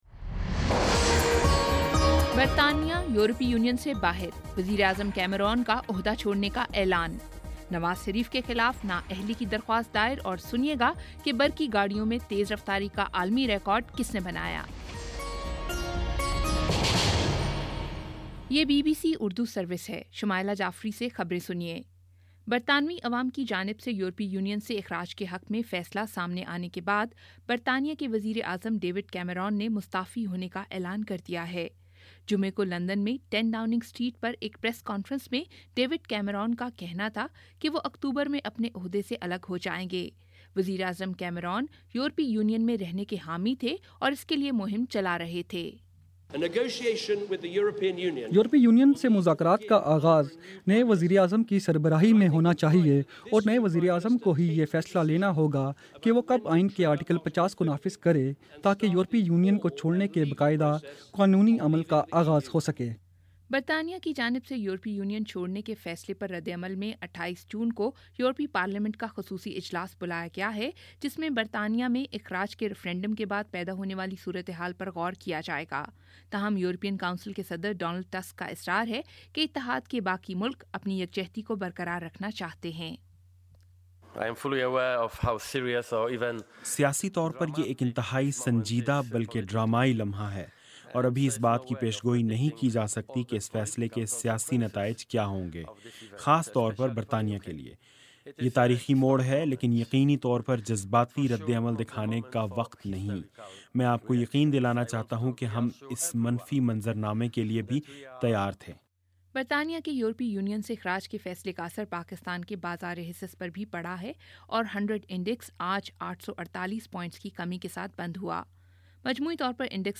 جون 24 : شام چھ بجے کا نیوز بُلیٹن